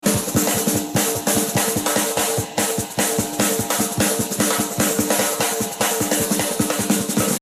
Возможно именно эта барабанная вставка является его работой.